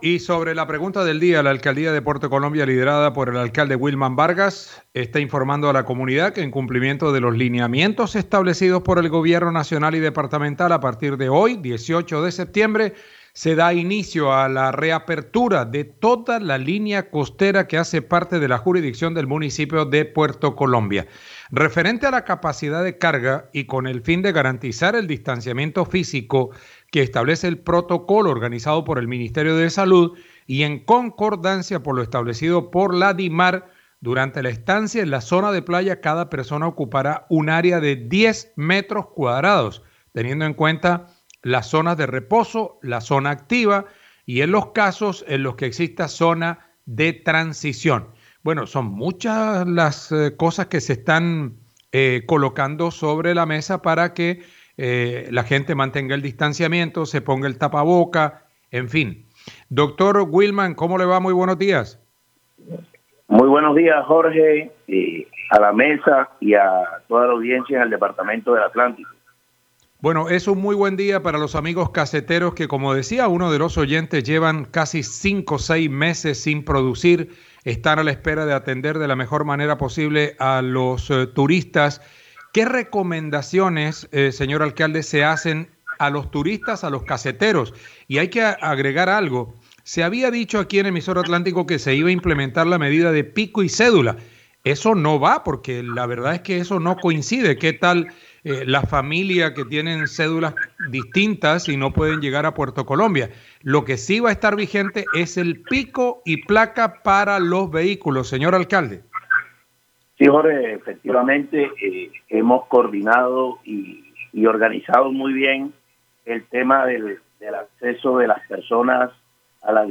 El Alcalde de Puerto Colombia Wilman Vargas informó que para que el servicio de Taxi, sí aplica el “Pico y Placa” por la reapertura de las playas en ese municipio a partir de este viernes.